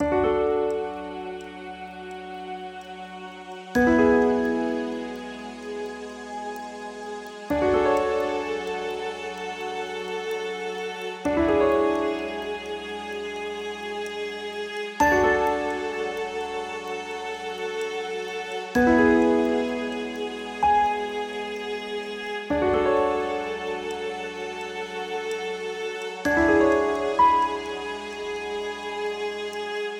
北欧のバラードをイメージした楽曲です！感動のシーンや異世界、孤独、寂しいシーンにぴったり！
BPM：64 キー：Dリディアン ジャンル：ゆったり、おしゃれ 楽器：ストリングス、シンセサイザー